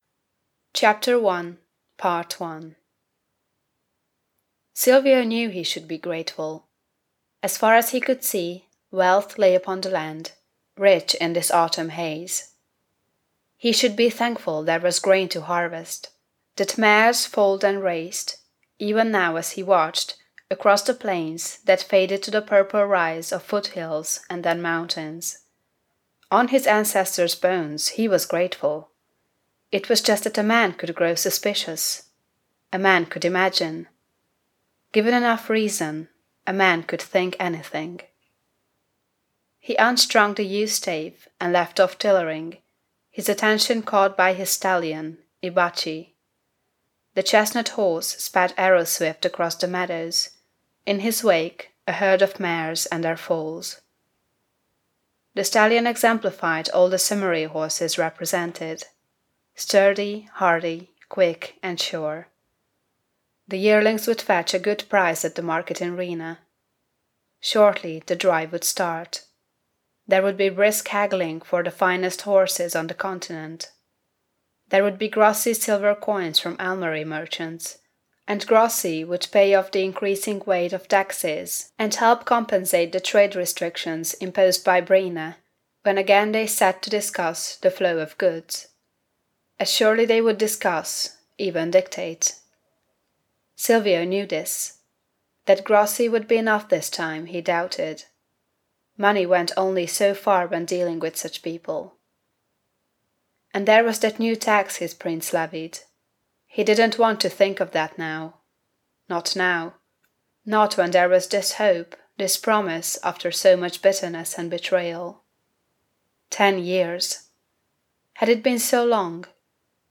It’s a perfect pairing with any Halloween festivities. 01 - Chapter 1, part 1